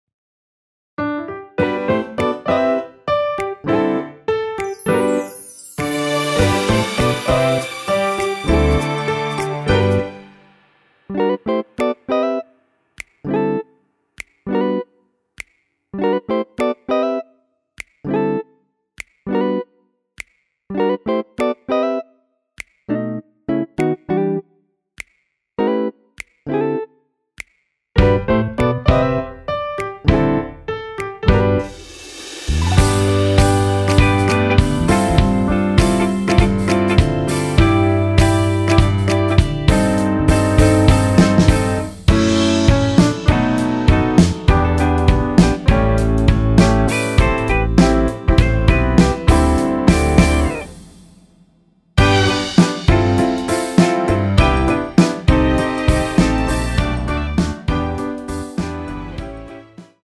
원키에서 (+4)올린 MR 입니다.
앞부분30초, 뒷부분30초씩 편집해서 올려 드리고 있습니다.
중간에 음이 끈어지고 다시 나오는 이유는